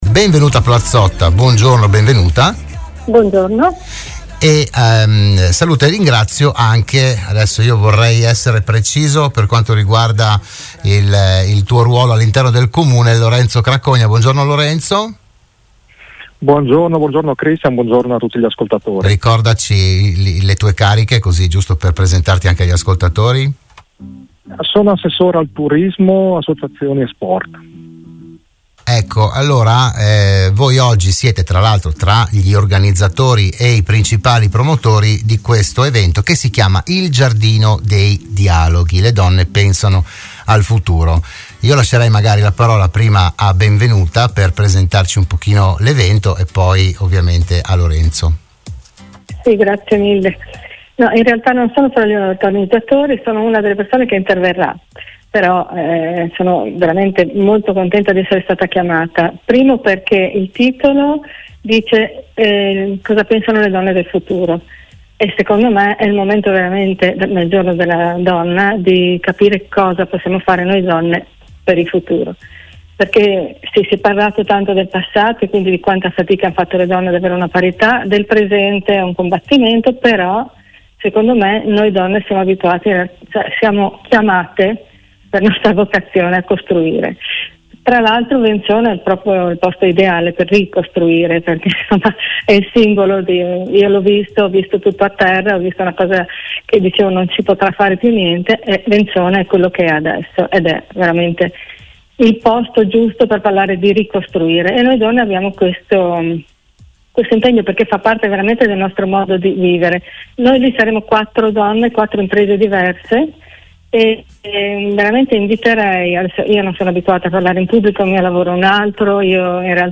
Ne hanno parlato a Radio Studio Nord